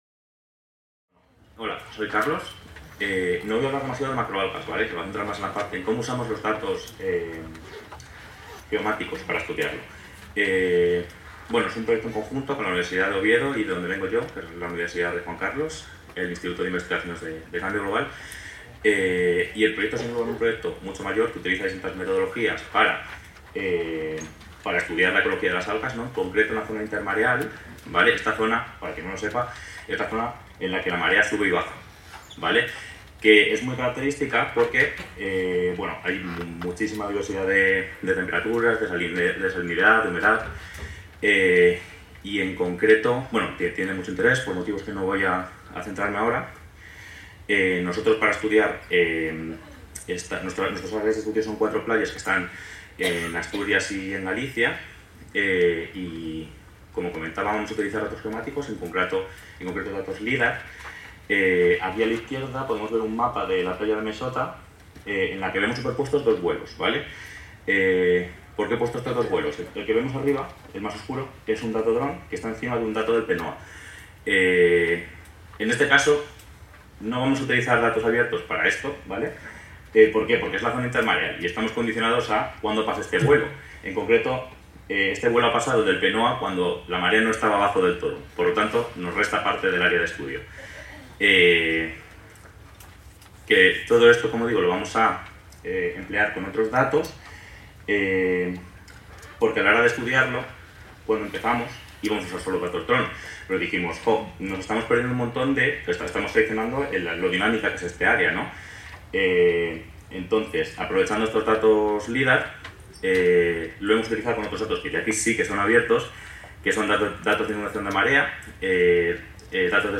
Comunicació
en el marc de les 18enes Jornades de SIG Lliure 2025 organitzades pel SIGTE de la Universitat de Girona. Ens presenta un estudi sobre la variabilitat en l'estat de quatre poblacions de macroalgues fucoides a la zona de la costa nord d'Espanya.